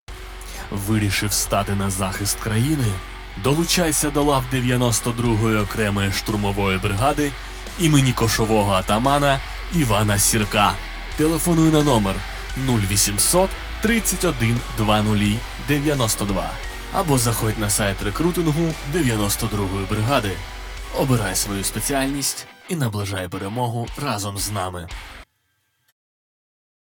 Соціальна реклама в ефірі